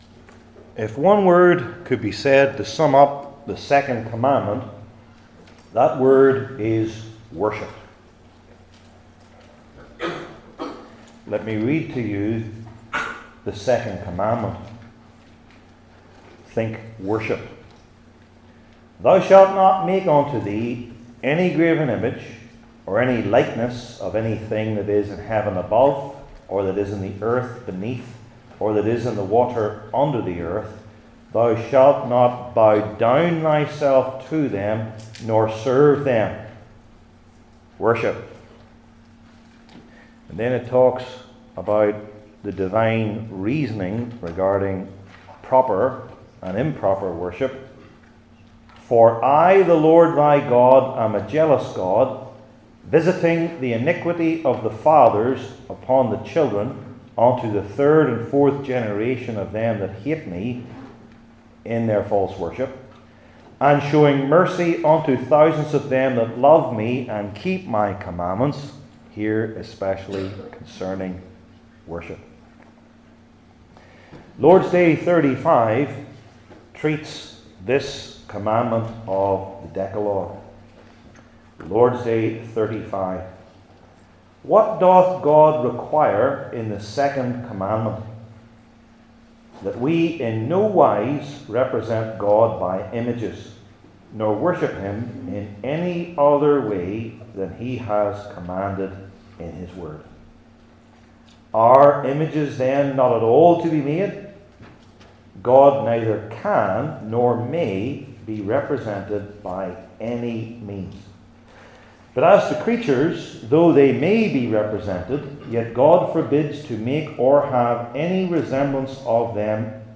Heidelberg Catechism Sermons I. Where?